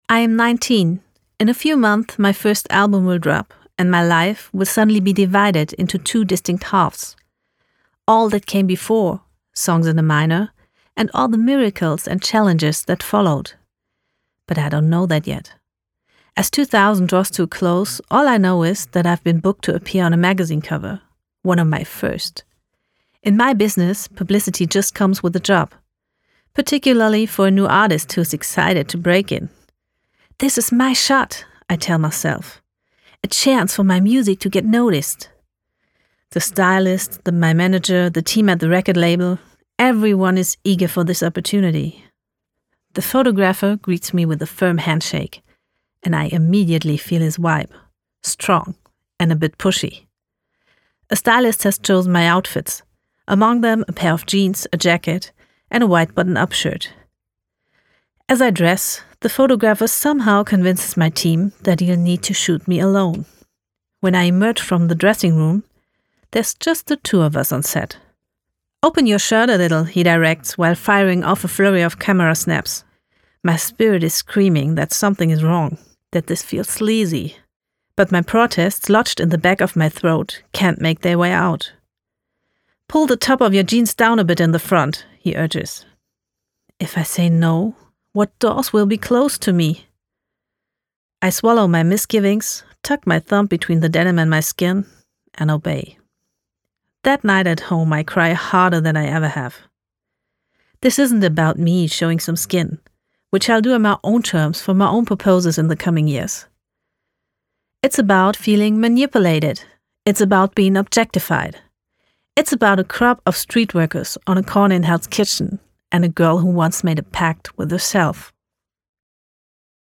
Professionelle Studiosprecherin.
Englisch